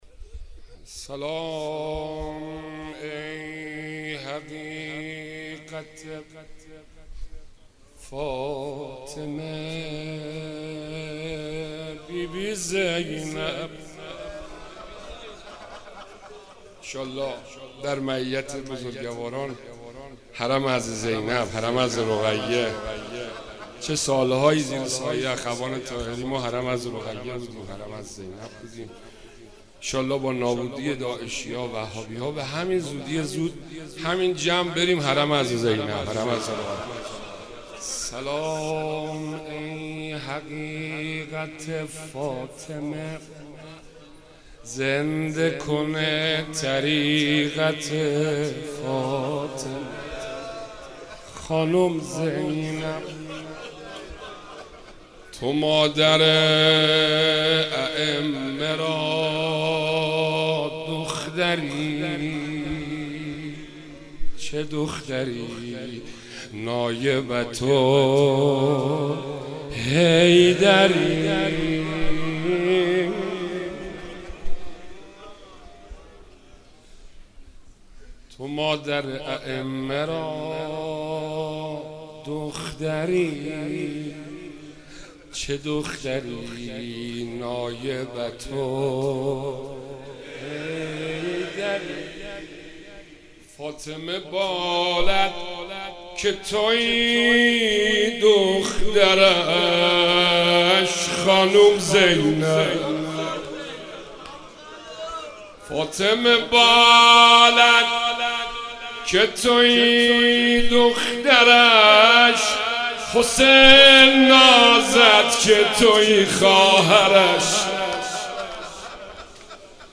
روضه
مناسبت : شب بیست و چهارم رمضان